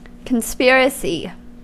Ääntäminen
US
IPA : /kənˈspirəsiː/